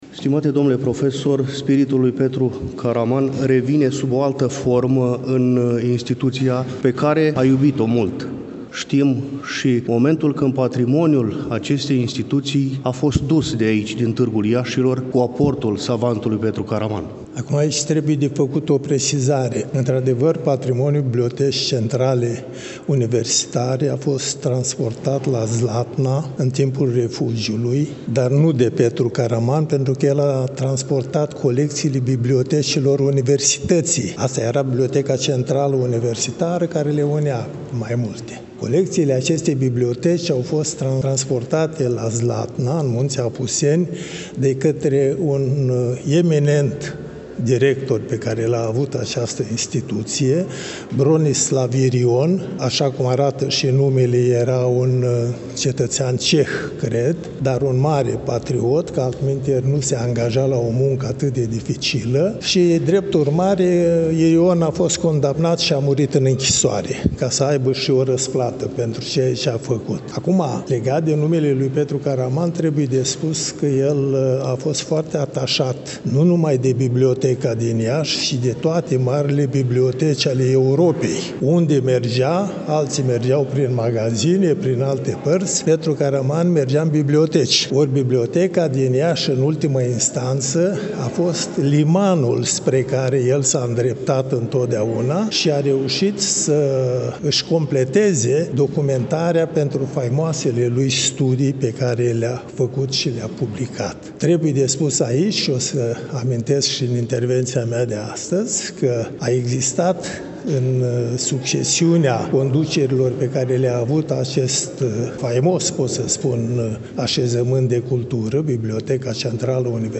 Cu prilejul împlinirii, la 14 decembrie 2023, a 125 de ani de la nașterea cel mai mare etnolog român, la Iași, în Sala „Hasdeu” din incinta BCU „Mihai Eminescu”, a fost organizat un eveniment de înaltă ținută academică.